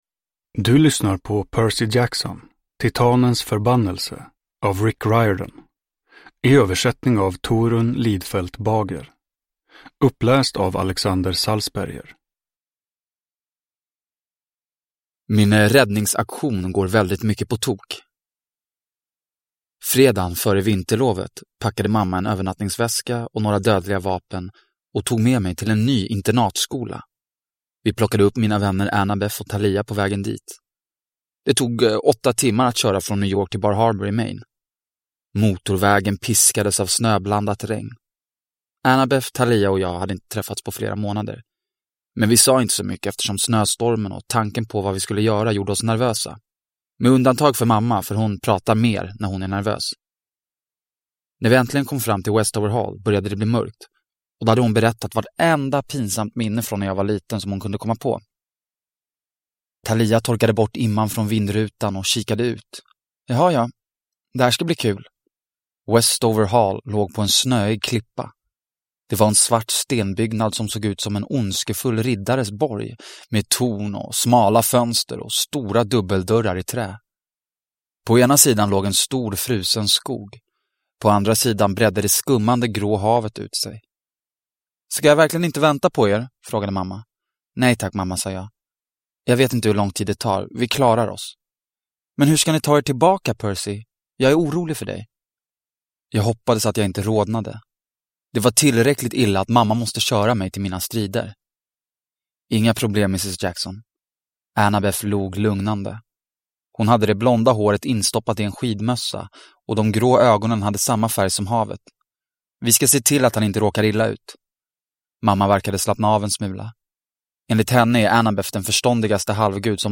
Titanens förbannelse – Ljudbok – Laddas ner